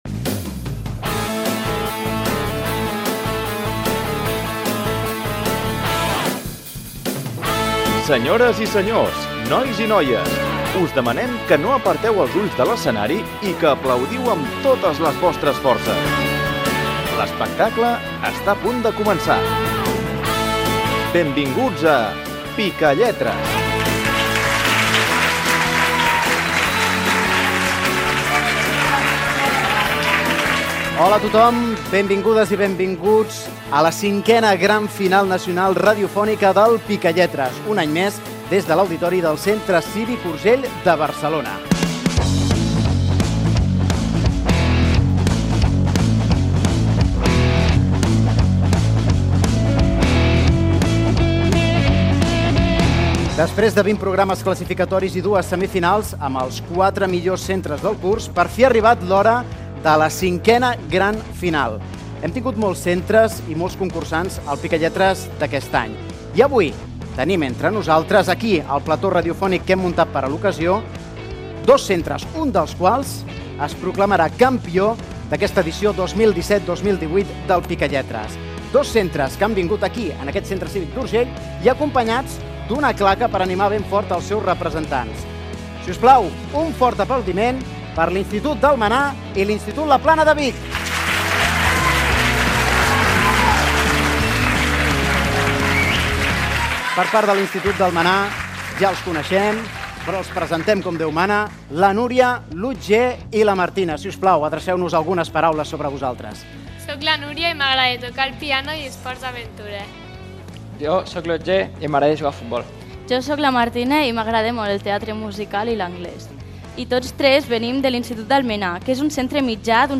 Fragment de la cinquena final del concurs des de l'auditori Centre Cívic l'Urgell de Barcelona Gènere radiofònic Participació